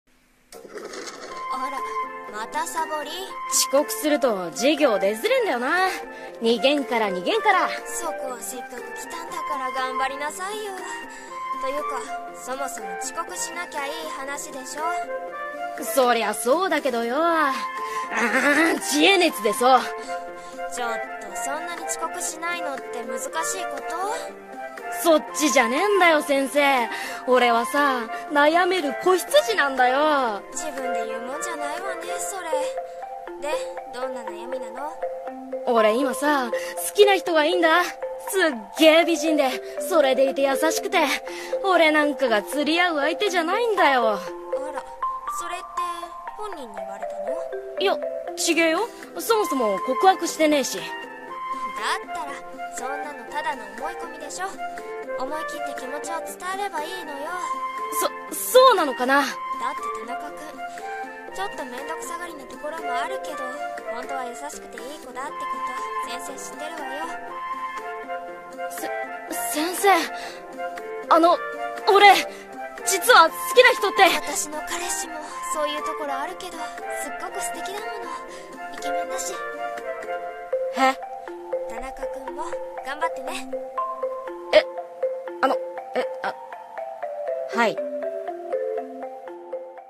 【コラボ声劇】先生俺と付き合って！！